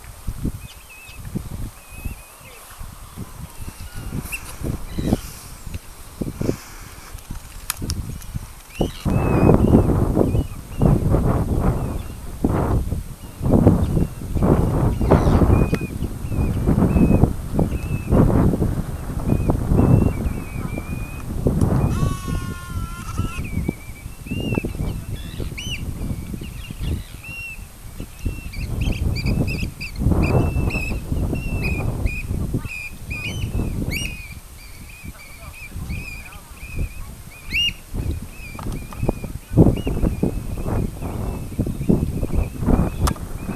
Auch hier in der Stille fallen wieder die vielen Vögel und ihre
Rufe auf.